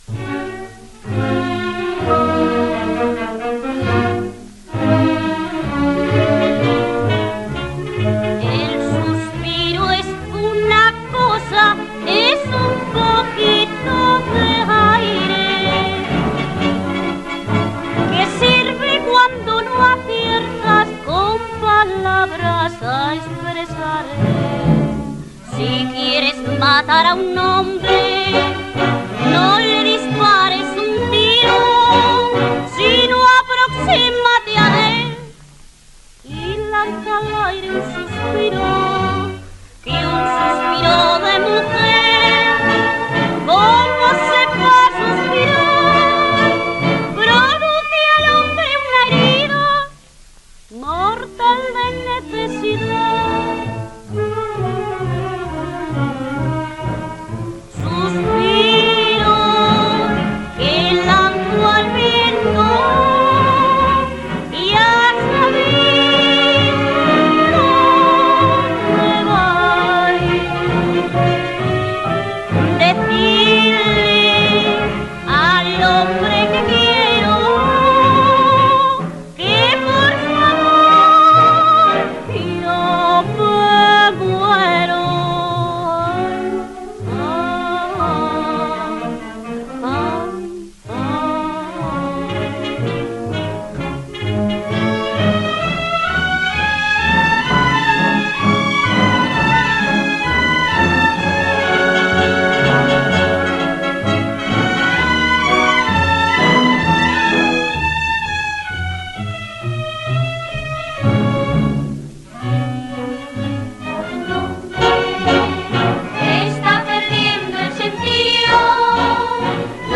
tango-bolero
78 rpm.